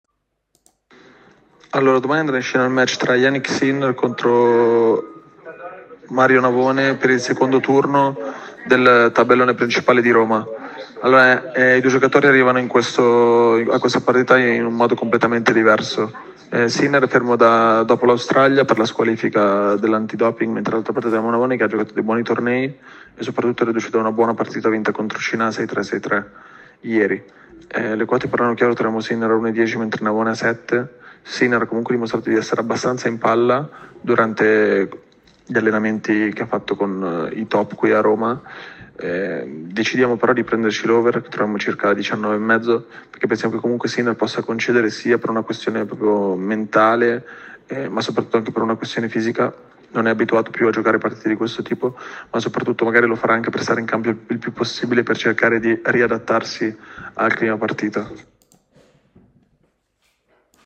Ecco l’audio analisi